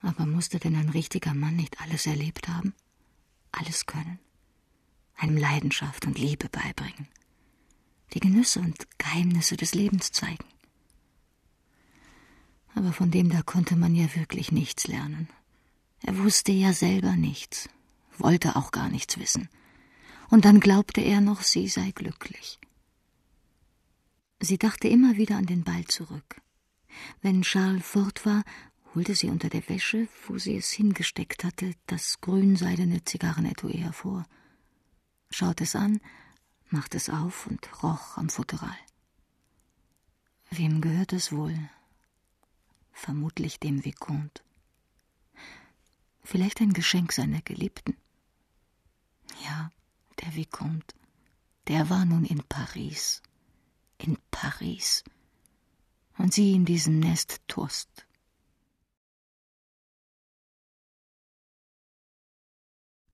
ausdrucksstark,charaktervoll, auch dunkles Timbre, sprachrhythmisch gewandt, warmer Erzählton, klarer journalistischer Ton, klangvoll, geschmeidig
Sprechprobe: Sonstiges (Muttersprache):
female german speaker with warm and colourful voice